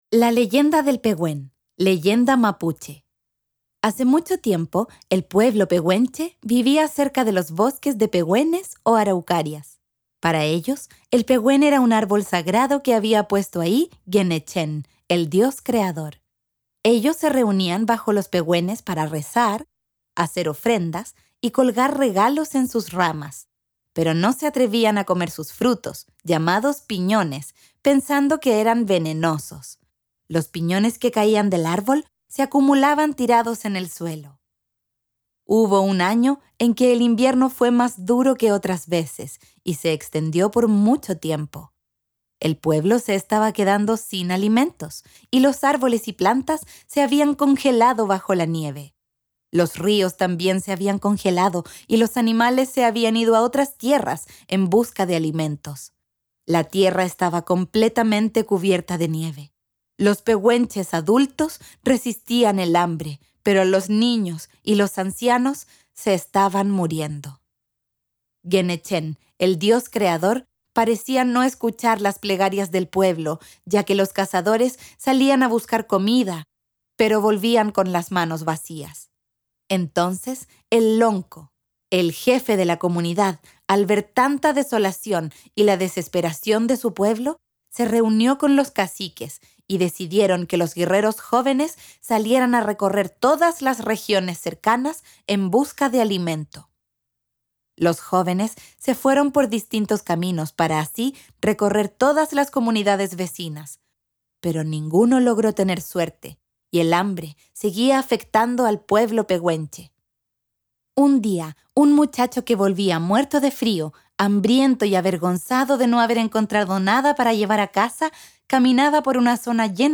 Audiocuentos